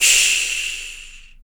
Index of /90_sSampleCDs/ILIO - Vocal Planet VOL-3 - Jazz & FX/Partition H/1 MALE PERC
CYMBAL 002.wav